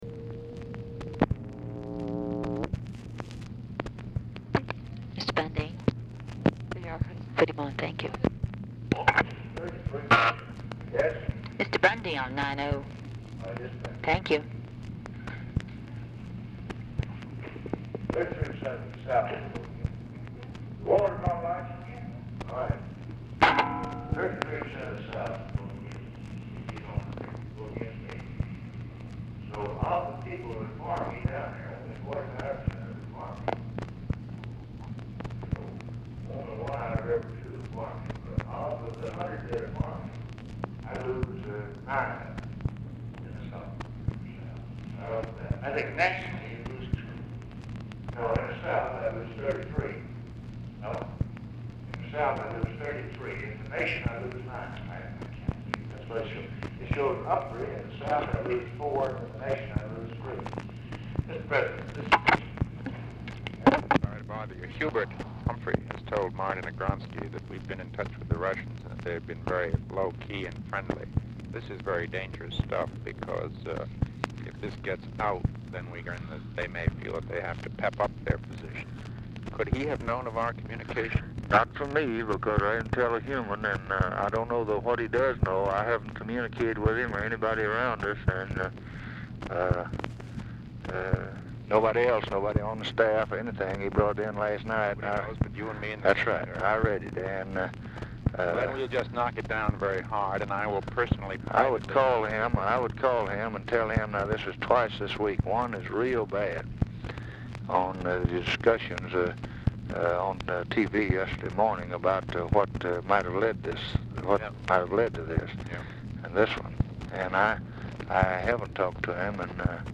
Telephone conversation # 4775, sound recording, LBJ and MCGEORGE BUNDY, 8/6/1964, 1:29PM | Discover LBJ
OFFICE CONVERSATION PRECEDES CALL
Format Dictation belt
Location Of Speaker 1 Oval Office or unknown location